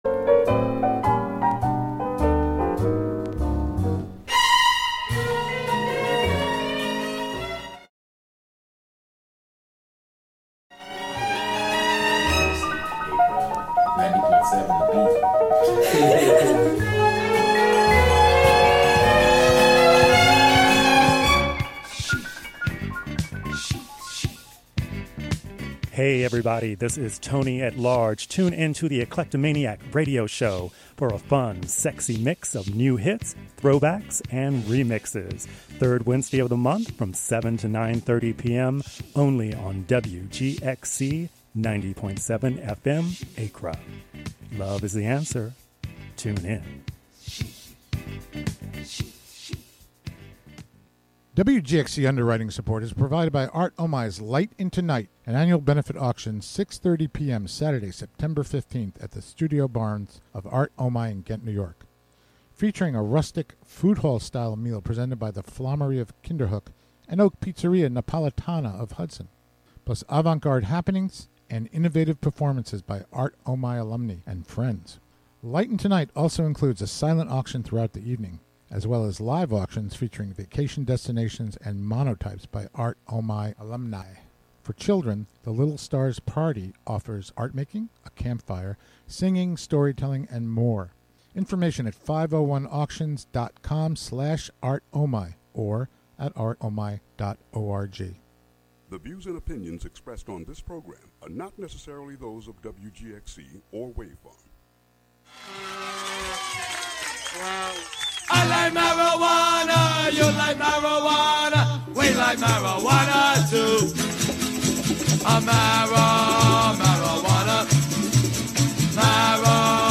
A one-hour call-in program featuring conversations...